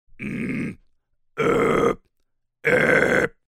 False cord scream - phonation pressée
08Grunt-complexe-ou-False-Cord-Scream-phonation-legerement-pressee.mp3